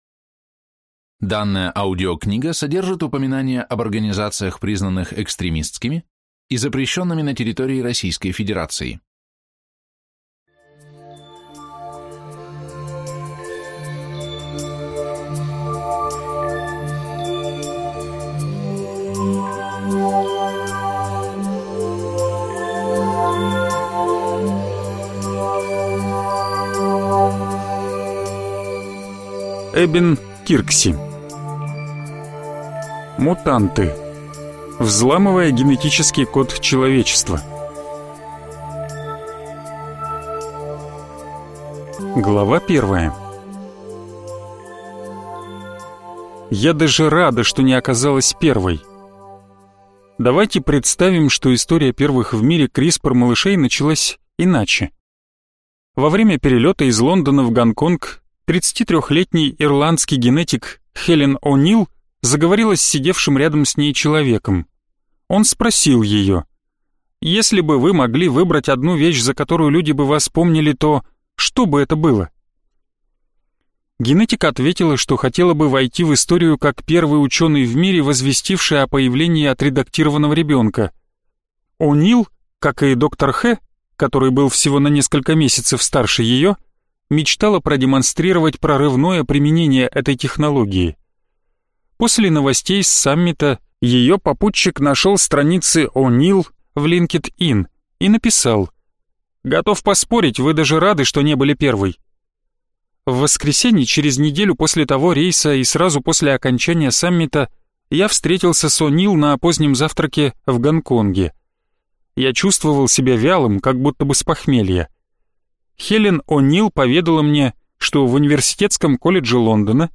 Аудиокнига Мутанты. Взламывая генетический код человечества | Библиотека аудиокниг